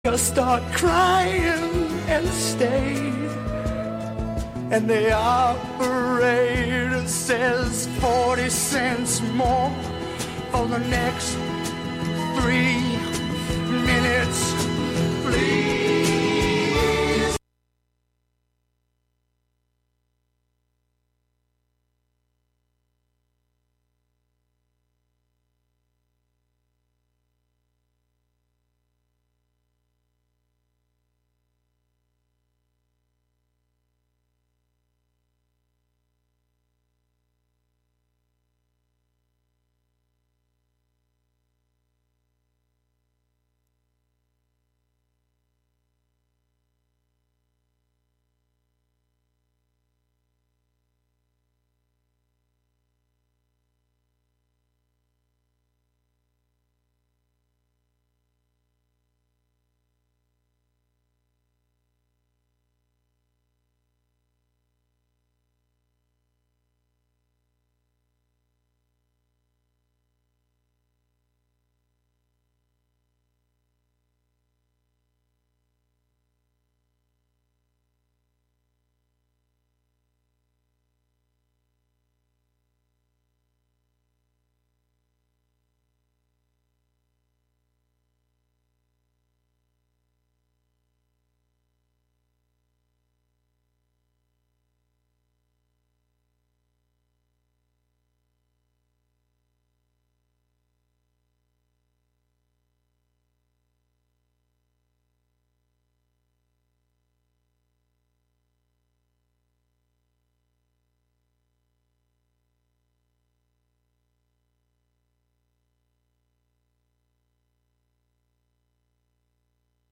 Monthly excursions into music, soundscape, audio document and spoken word, inspired by the wide world of performance. Live from Ulster County.